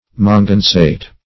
Manganesate \Man`ga*ne"sate\